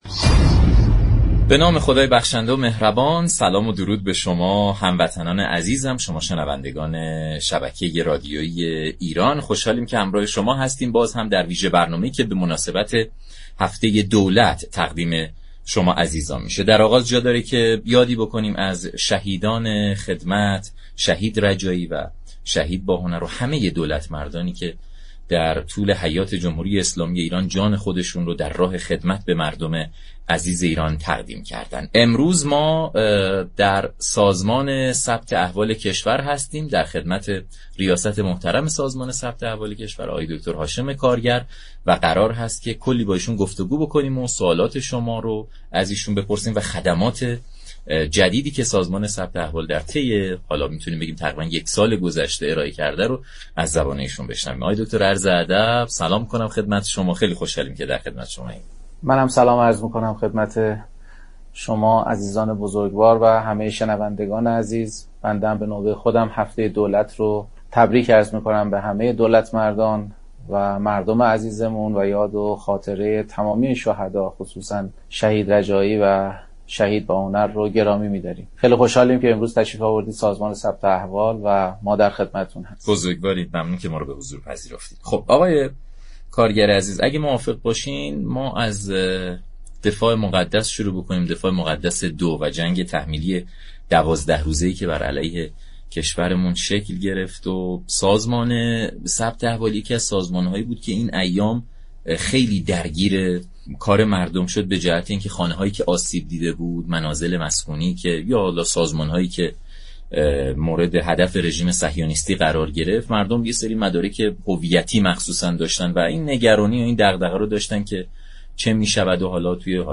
رییس سازمان ثبت احوال كشور در برنامه ایران‌امروز گفت: در طول جنگ 12 روزه سازمان بیش از 500 هزار خدمت ثبت احوالی و هویتی را برای مردم ارائه داد.